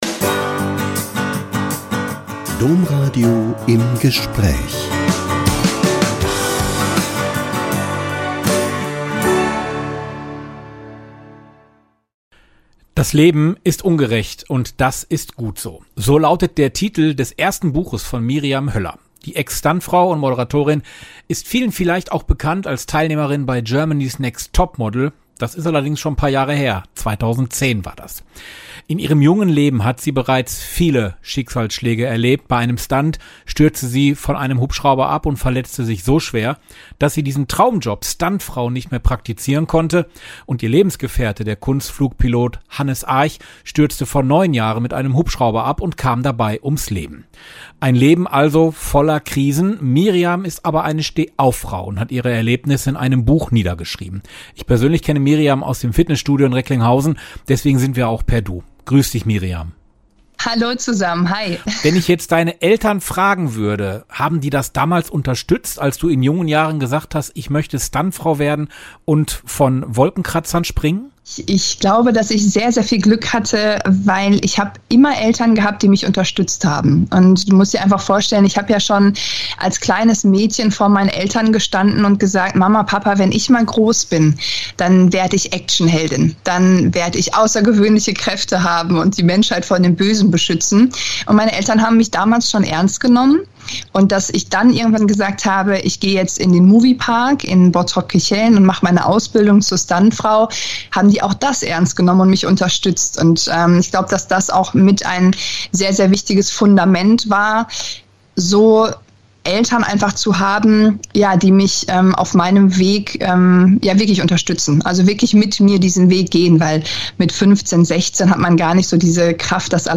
Ex-Stuntfrau Miriam Höller spricht über Rückschläge und Lebensmut - Ein Interview mit Miriam Höller (Ex-Stuntfrau, Moderatorin, Speakerin, Model und ehemalige GNTM-Teilnehmerin) ~ Im Gespräch Podcast